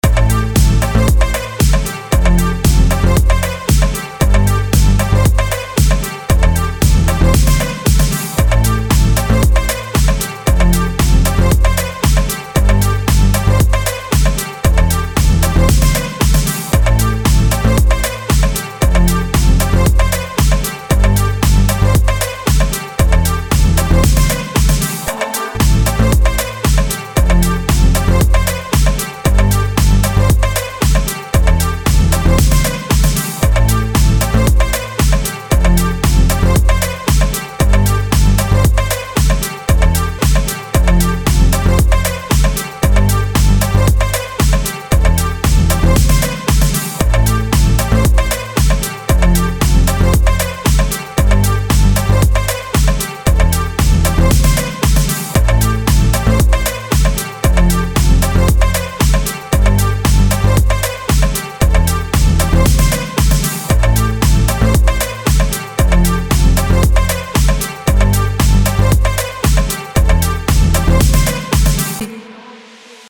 • Качество: 320, Stereo
громкие
Synth Pop
без слов
disco
минусовка